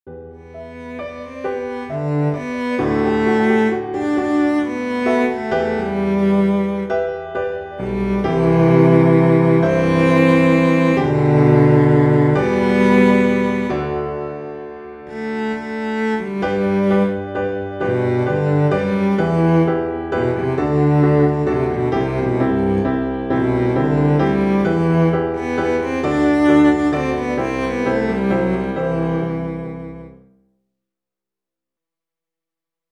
for Cello & Piano
works well as a solo for cello with piano accompaniment.